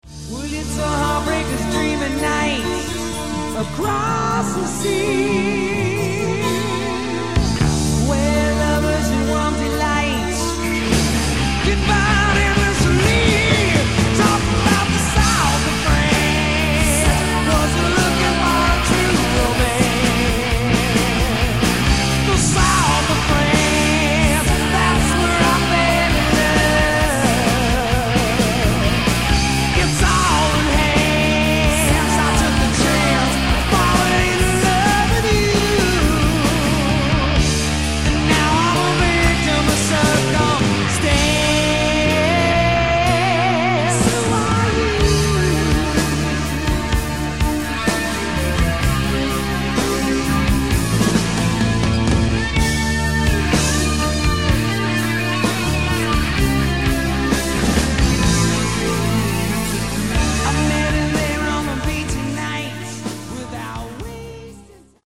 Category: Hard Rock
lead guitar, backing vocals
lead vocals
bass
drums